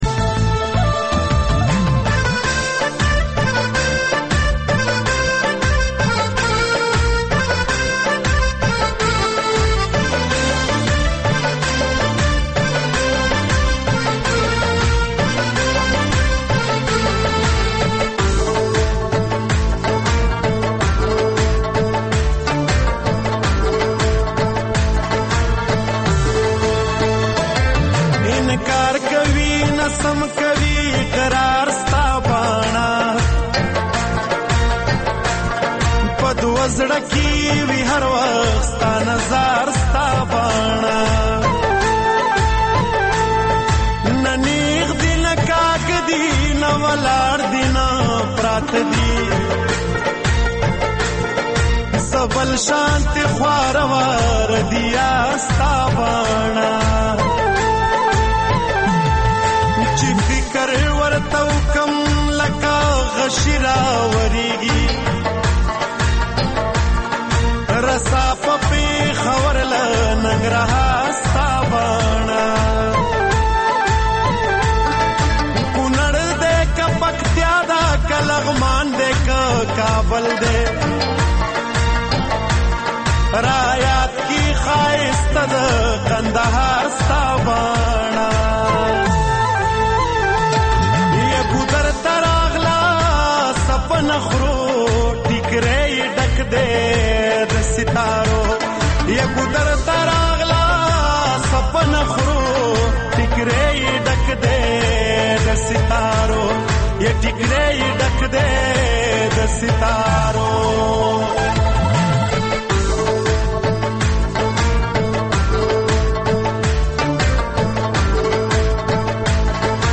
د سندرو مېلمانه ( موسیقي)